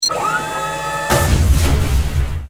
bolt.wav